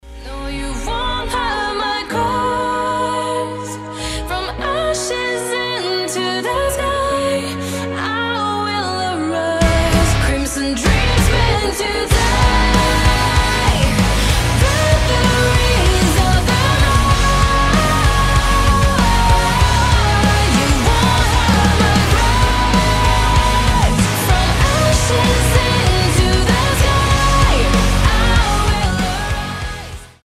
• Качество: 320, Stereo
громкие
женский голос
power metal
Modern metal